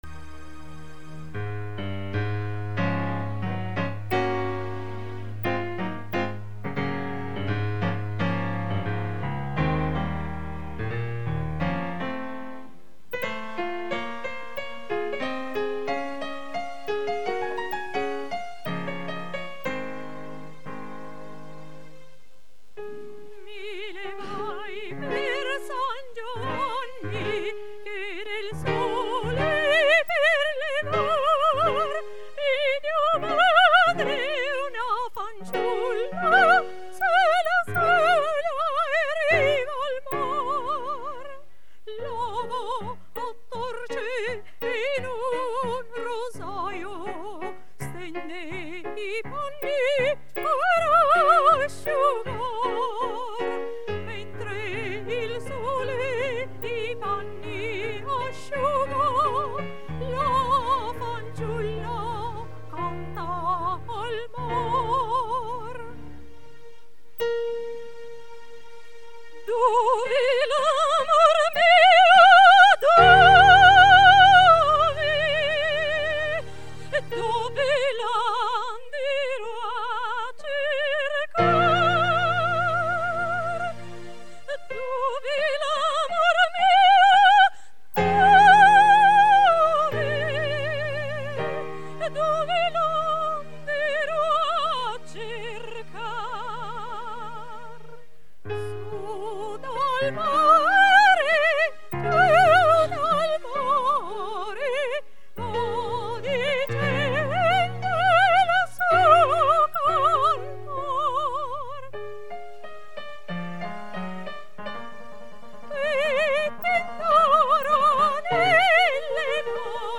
Coro "Rose di Gerico"
dal vivo